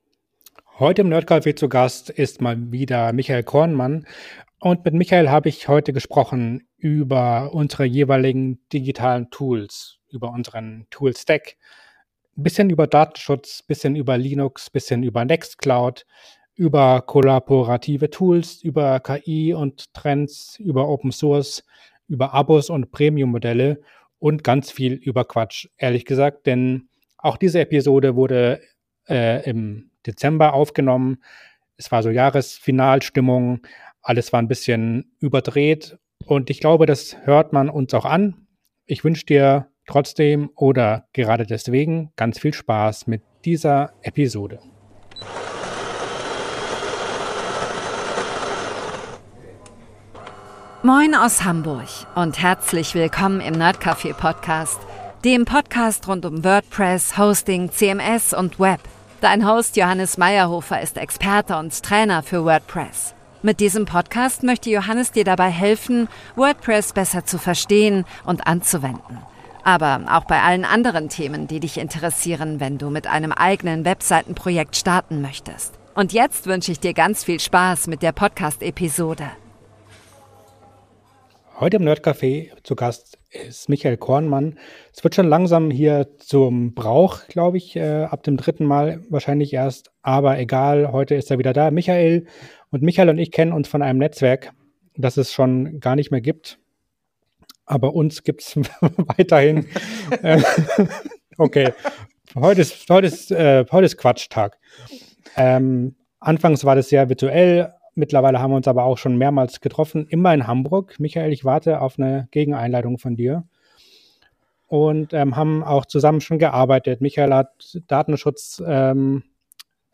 Wir haben deswegen heute gesprochen über: Digitale Tools, unser Lieblingsthema Datenschutz, Toolstack, Linux, die nextcloud, allgemein Open Source Software, Abo-Modelle, Premium Modelle und KI. Offenlegung: Wir haben die Episode kurz vor Jahreswechsel aufgenommen, sie kann also (viele) Spuren von Ironie, Albernheit und Quatsch beinhalten.